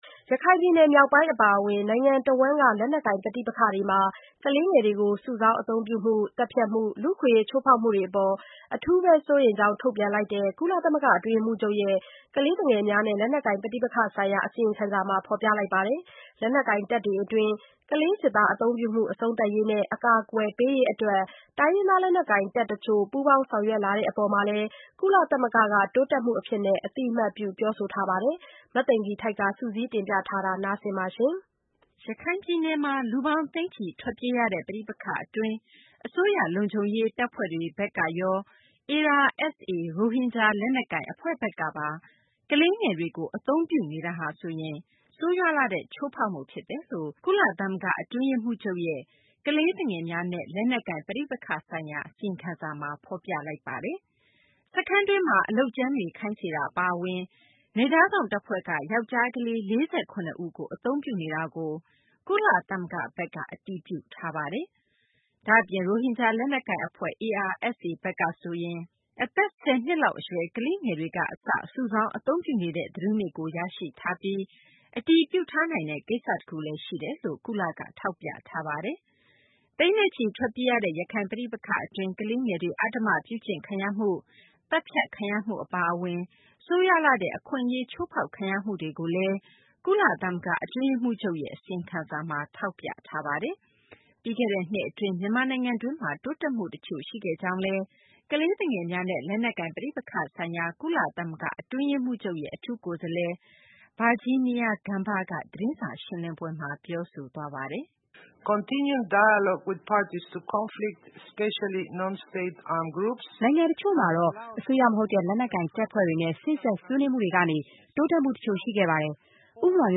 ပြီးခဲ့တဲ့ နှစ် အတွင်း မြန်မာနိုင်ငံတွင်း တိုးတက်မှု တချို့လည်း ရှိခဲ့ကြောင်း ကလေးသူငယ်များနှင့် လက်နက်ကိုင် ပဋိပက္ခဆိုင်ရာ ကုလသမဂ္ဂ အတွင်းရေးမှူးချုပ်ရဲ့ အထူးကိုယ်စားလှယ် Virginia Gamba က ဒီကနေ့ သတင်းစာ ရှင်းလင်းပွဲမှာ ပြောဆိုသွားပါတယ်။